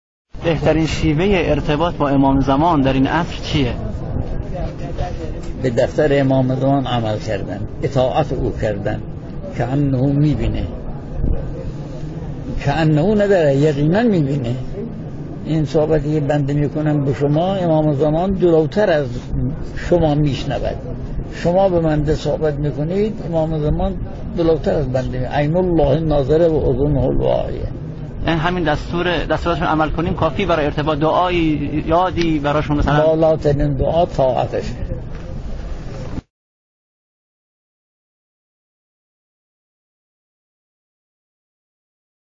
سخنرانی‌های آیت‌الله بهجت درباره امام زمان عجل‌الله